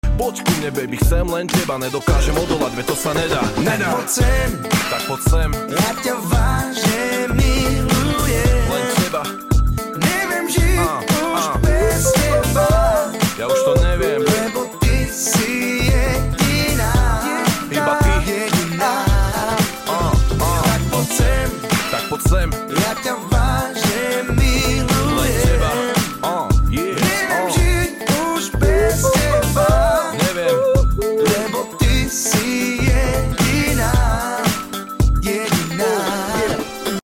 Balkan (slovakia) music